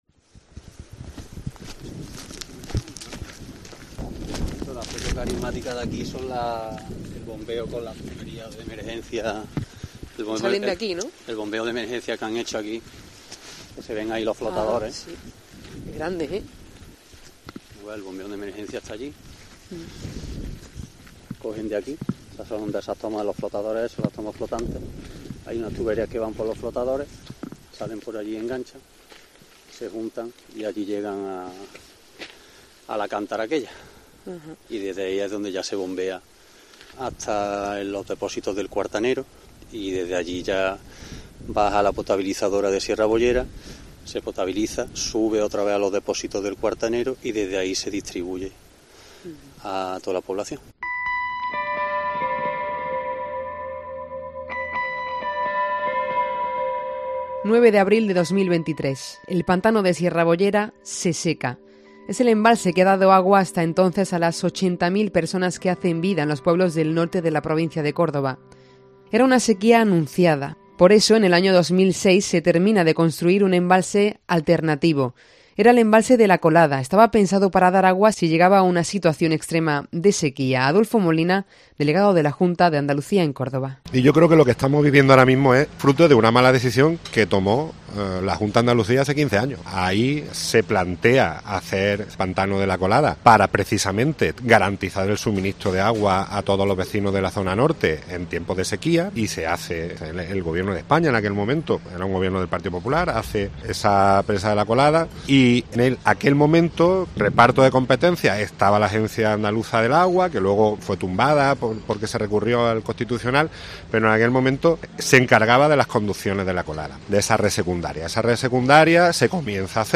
ESCUCHA AQUÍ EL REPORTAJE COMPLETO EN COPE CÓRDOBA No es hasta el año 2020 cuando empiezan a escucharse que La Colada puede ser realmente una alternativa para el consumo humano.